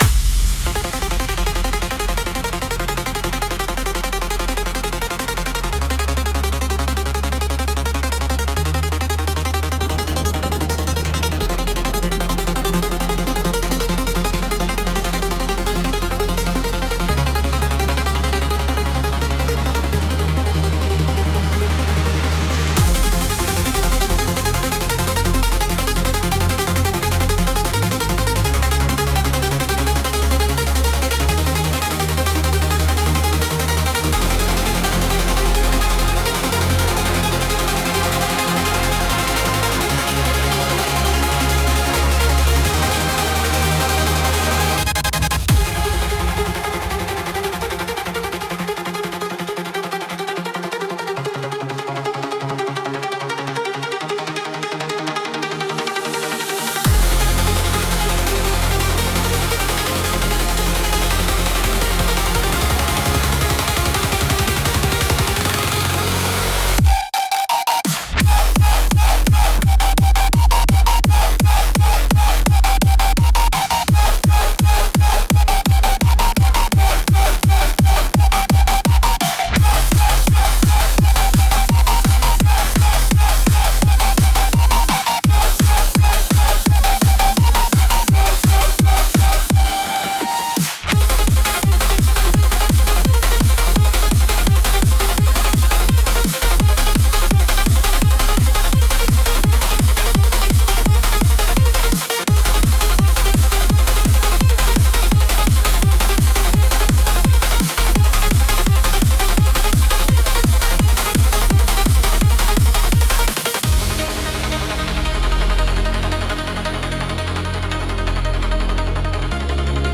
Five high-impact electronic tracks.
Built for late-night energy and controlled impact.